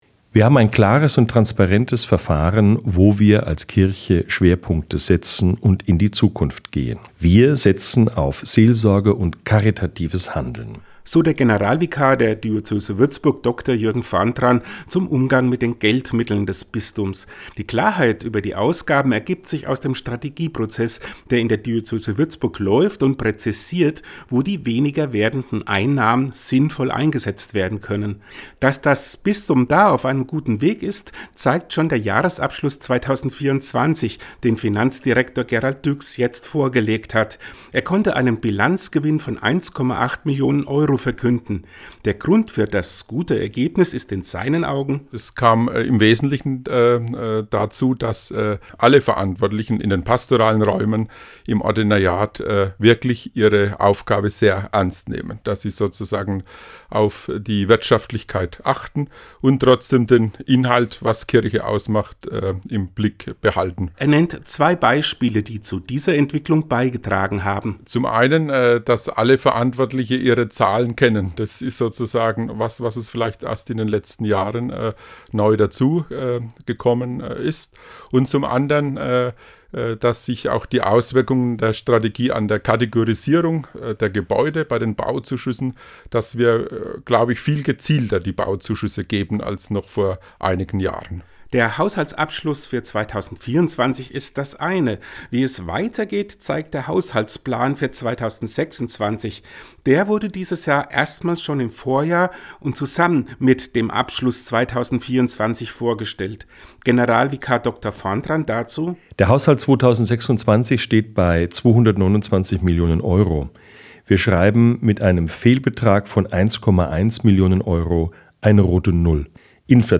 Den Radiobericht gibt es unten als Download